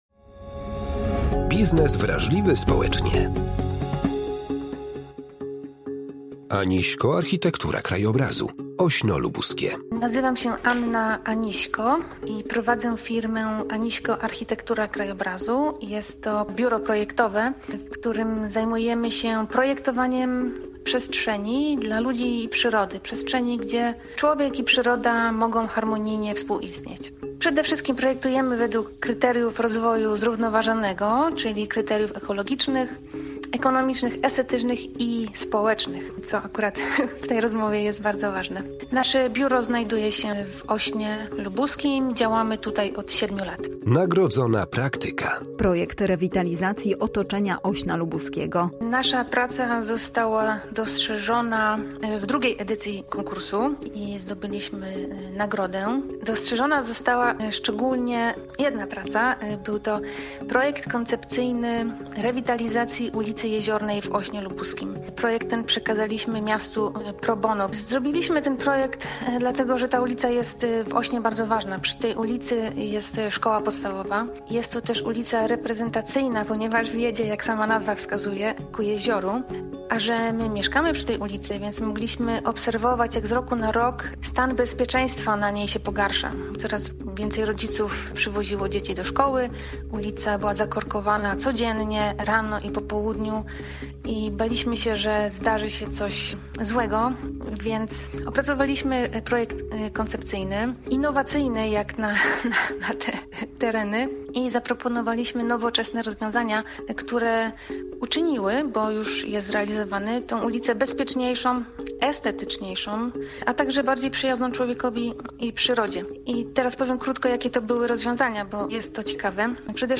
W ramach przybliżania dobrych praktyk, zapraszamy do wysłuchania rozmowy